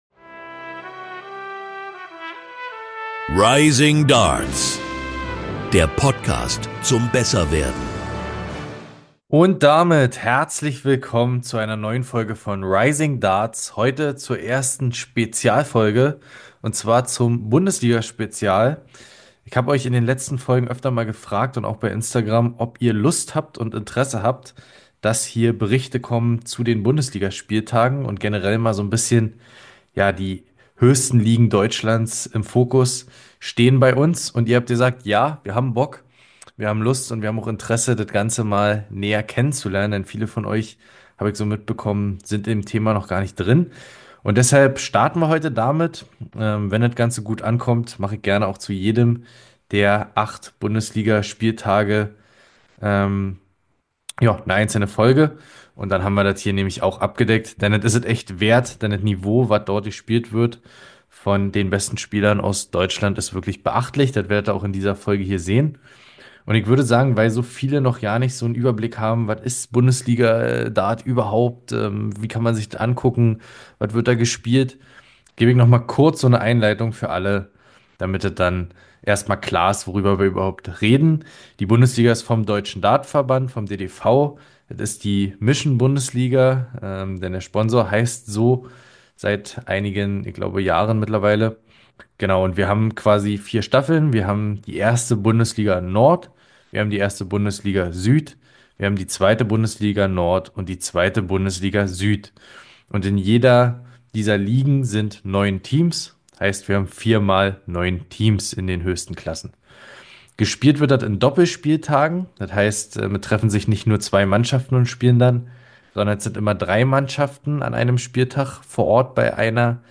In dieser Folge findet ihr alle Infos über die höchsten 4 Ligen Deutschlands im Steeldart. Mit Beiträgen von Bundesliga-Spielern, die direkt vor Ort waren.